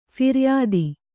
♪ phiryādi